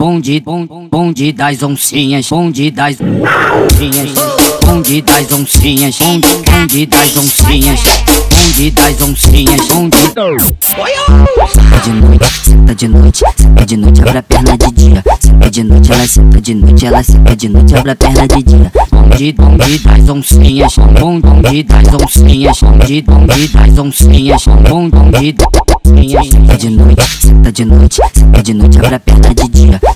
Жанр: Иностранный рэп и хип-хоп / Фанк / Рэп и хип-хоп